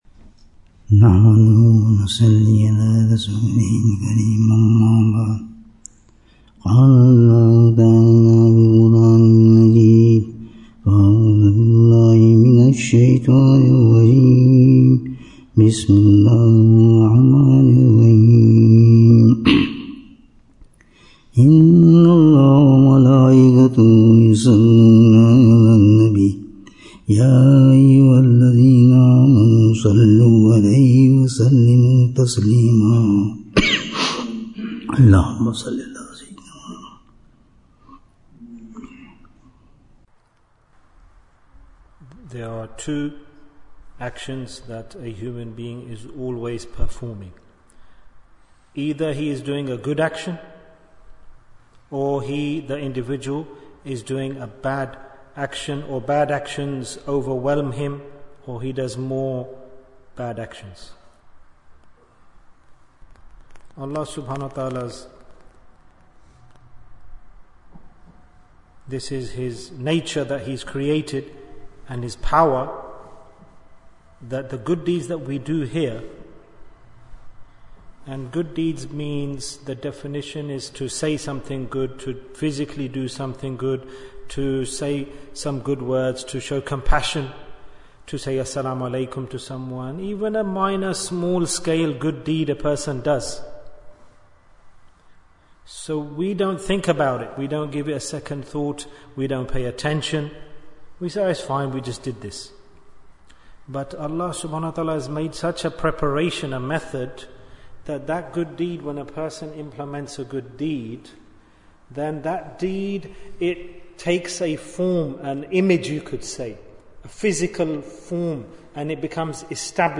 Retribution for Deeds Bayan, 19 minutes1st August, 2024